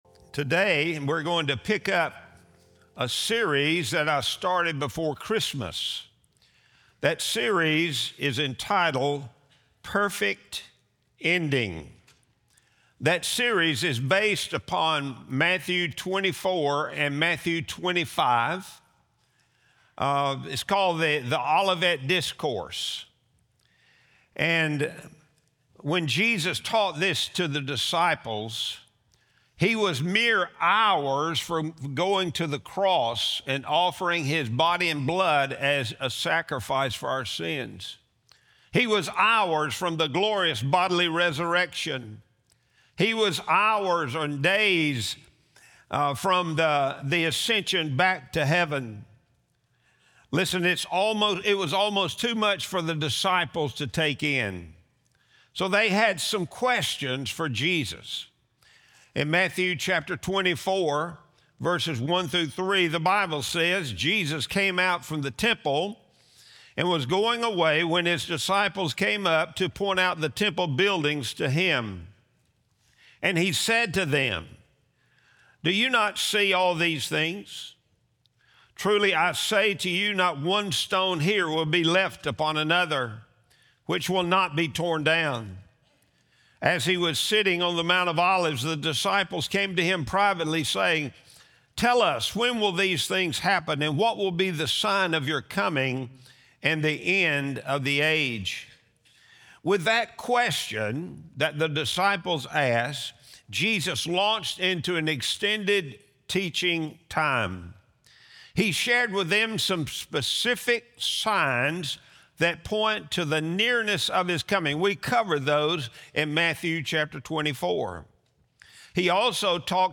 Sunday Sermon | January 11, 2026